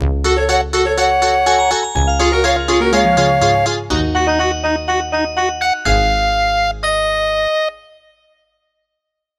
Pulled from game files by uploader
Converted from .mid to .ogg
Fair use music sample